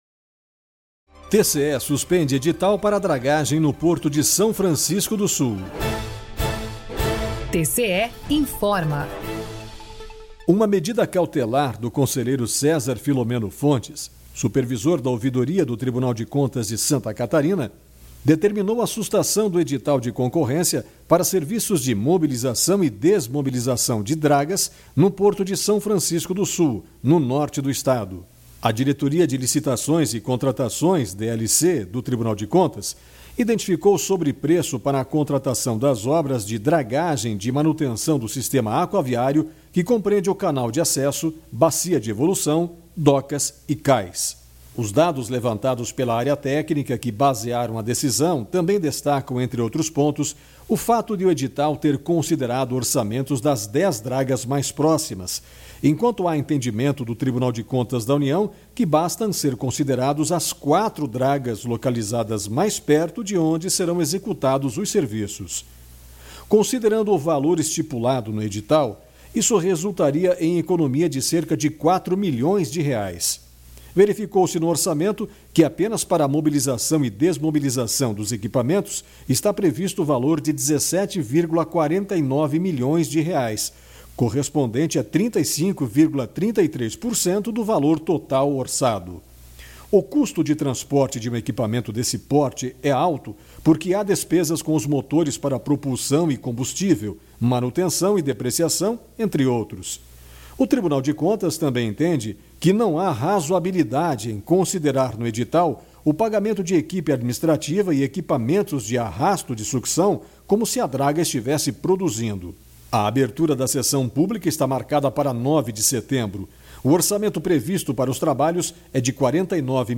VINHETA TCE INFORMA